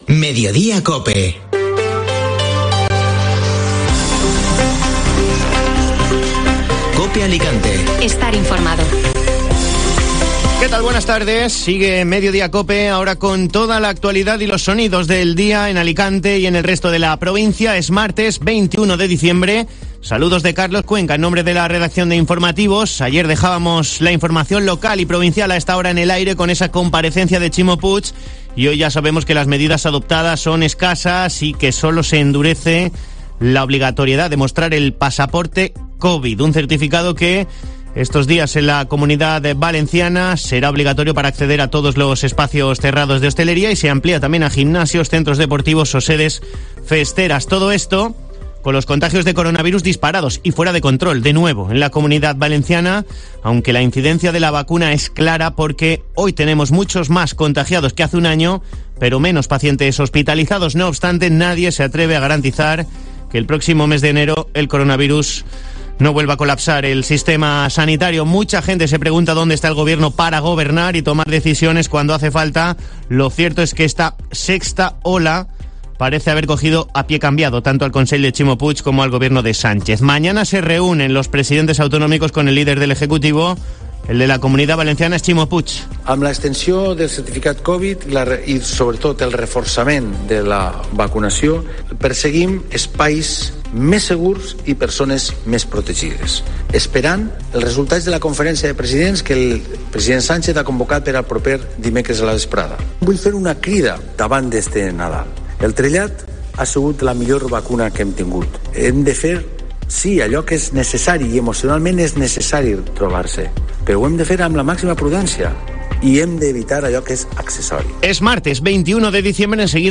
AUDIO: Escucha las noticias de este martes en Alicante: El coronavirus, disparado en la provincia.